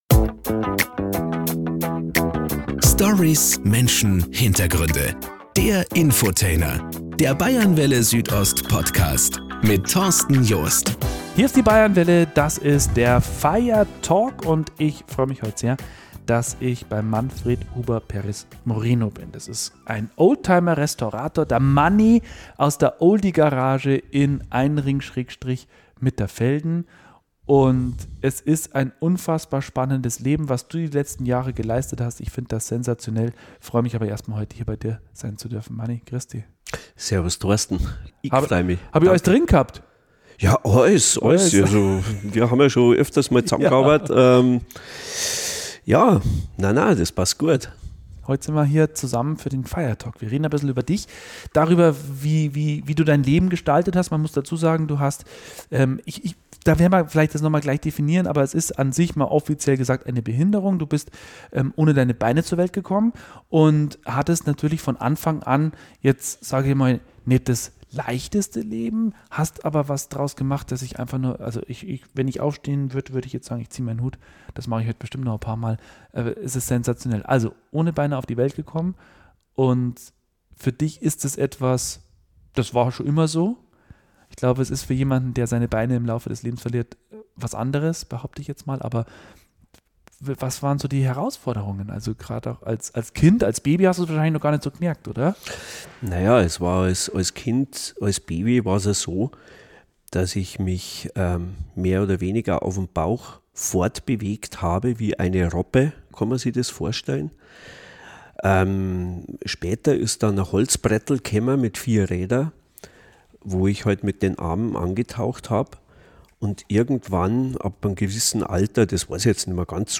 18.04.2025: Radiosendung Bayernwelle Südost